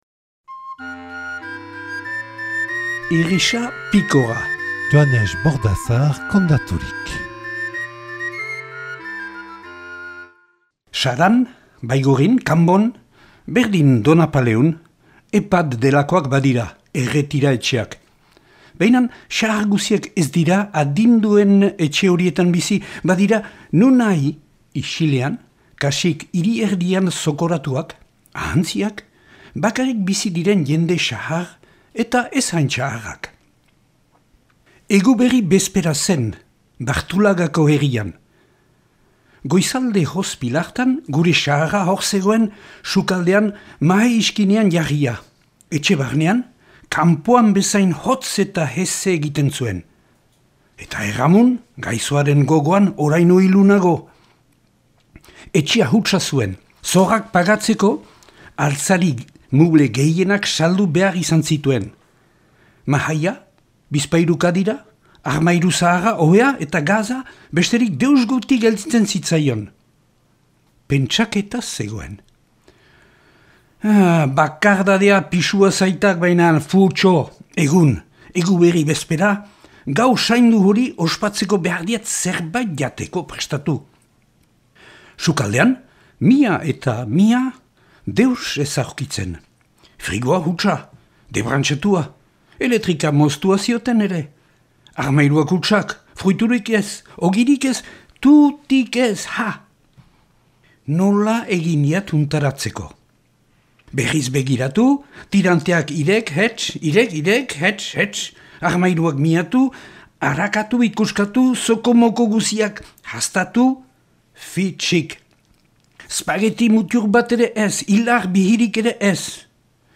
Ipuina "Irrisa pikoa"